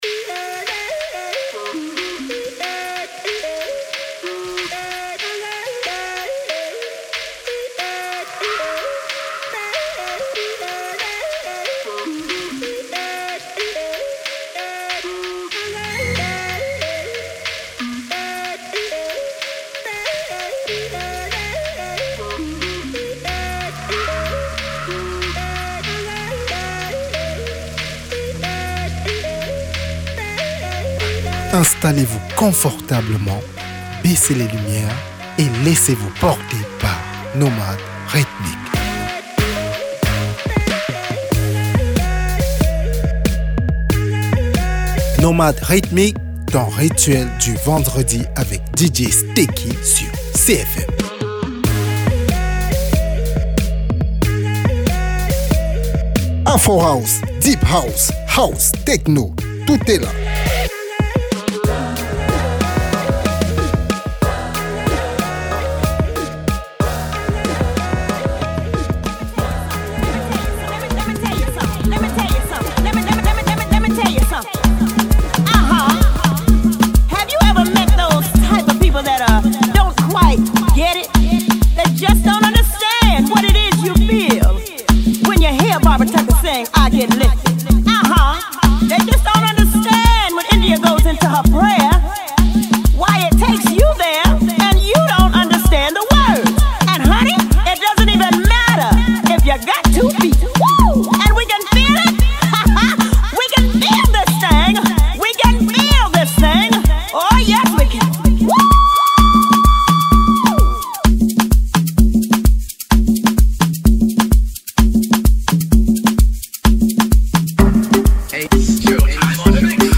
Prenez un grand bol d’air, on vous emmène en plongée deep, deep, deep. Au programme de votre rituel du vendredi, Afro Deep House Minimal Techno.